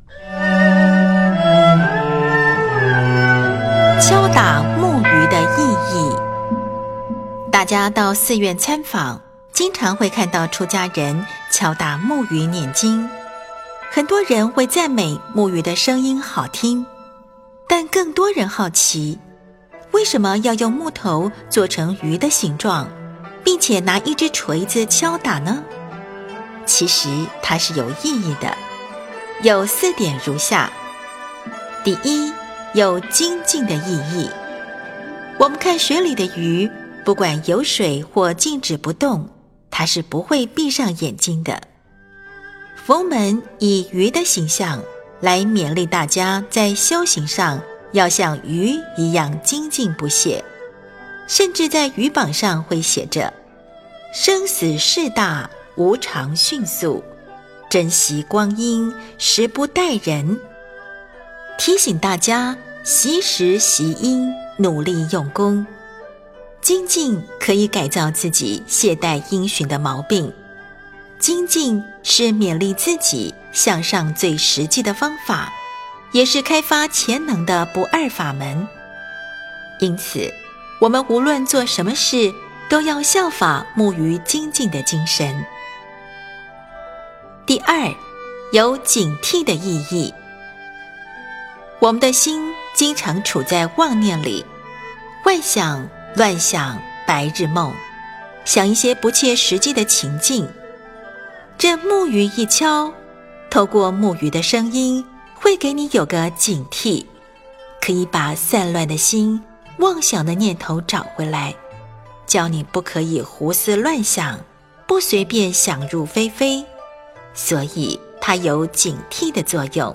标签: 佛音冥想佛教音乐